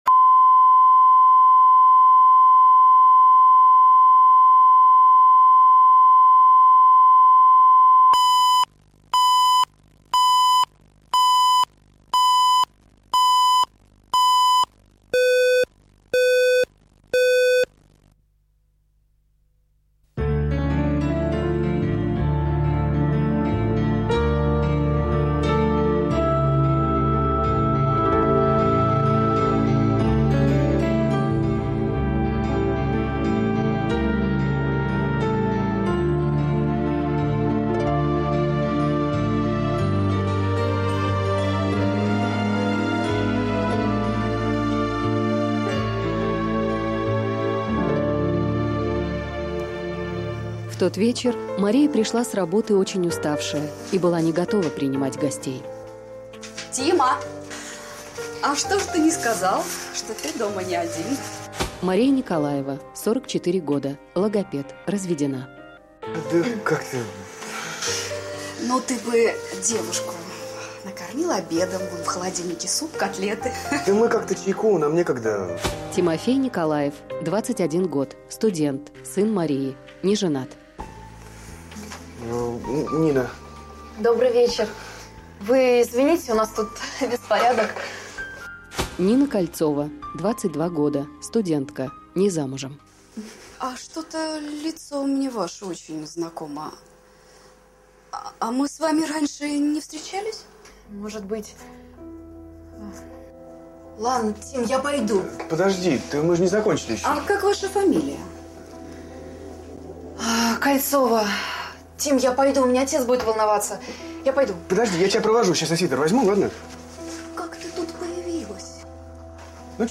Аудиокнига Степан да Марья | Библиотека аудиокниг